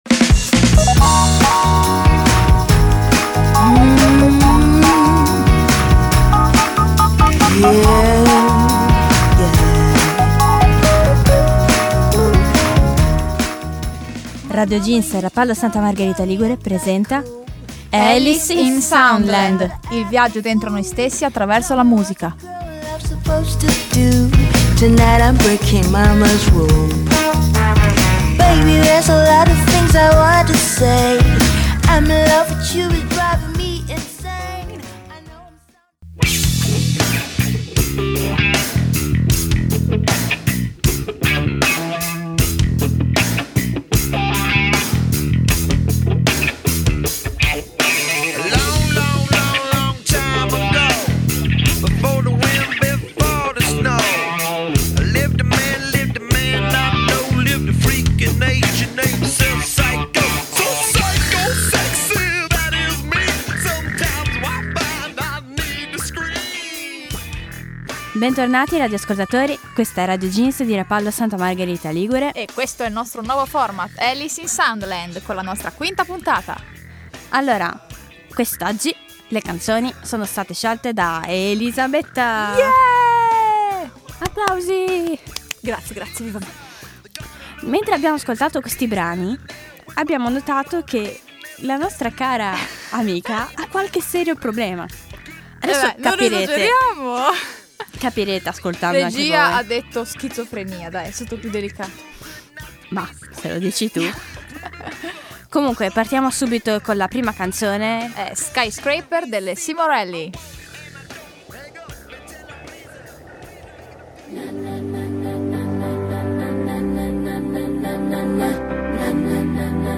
Tra Metal e metafora, tra arpe e chitarre distorte, l'aria leggiadra del pop e l'incedere soffuso della tragedia operistisca continua il nostro viaggio musicale.